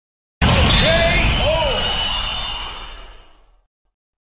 Âm thanh hạ gục K.O (Knockout) đánh nhau
Thể loại: Đánh nhau, vũ khí
Description: Âm thanh hạ gục (Knockout) K.O Sound Effect, Mortal Kombat K.O, trận chiến đấu.
am-thanh-ha-guc-k-o-knockout-danh-nhau-www_tiengdong_com.mp3